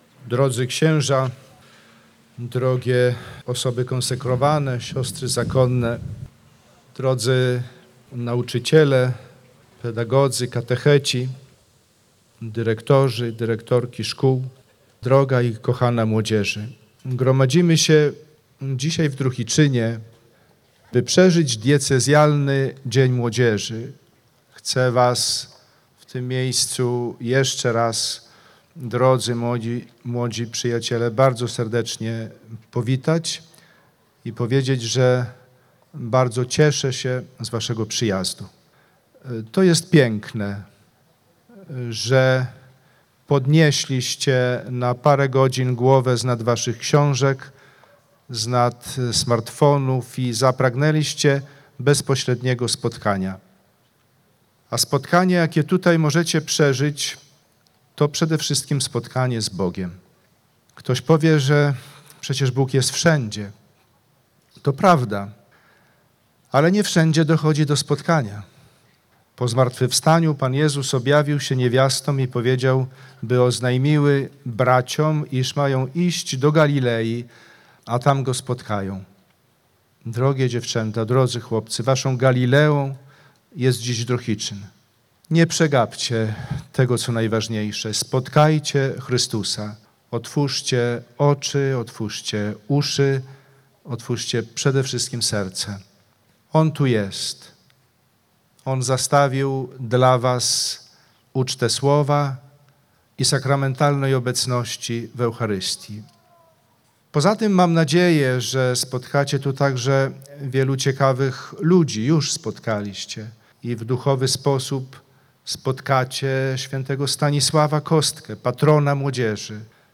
Blisko 2 tys. młodzieży, katechetów, nauczycieli i opiekunów wzięło udział w Diecezjalnym Dniu Młodzieży.